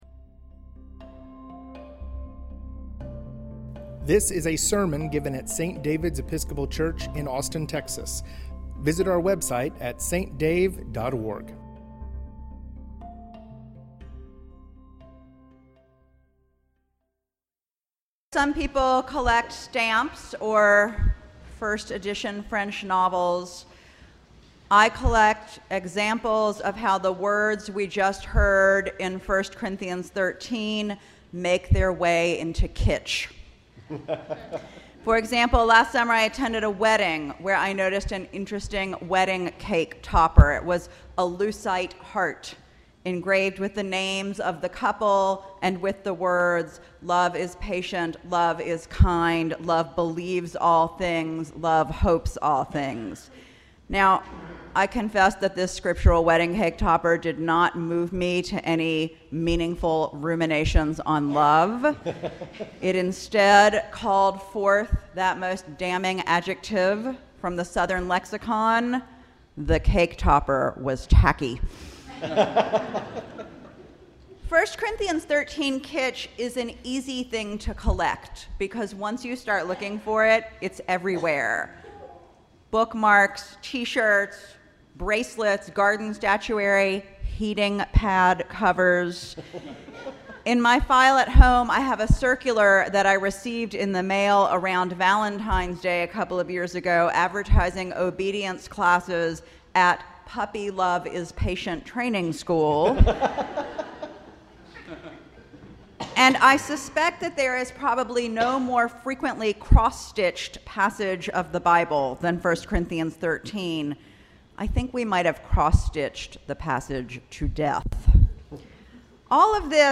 Sermon: The Rev. Dr. Lauren Winner, "Love As Jesus Does" February 03, 2019